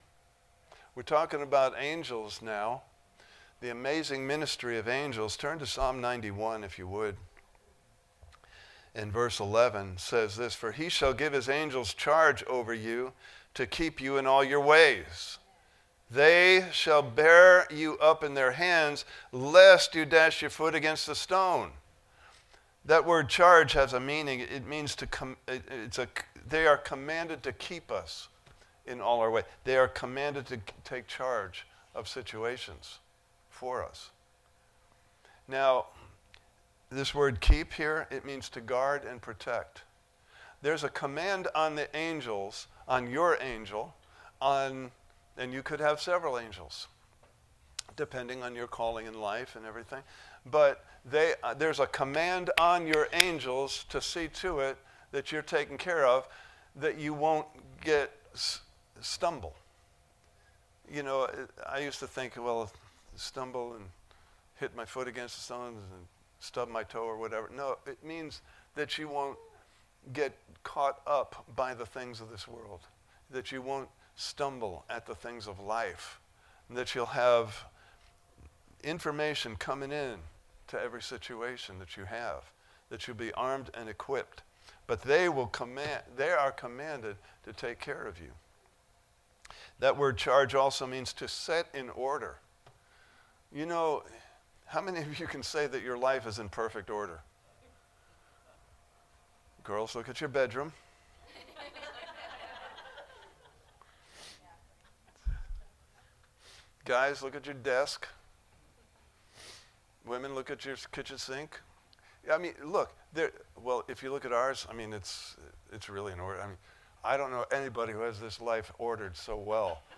Service Type: Sunday Morning Service « Part 3: Types of Angels (Video) Part 4: Jesus Has Given Angels Charge Over Us!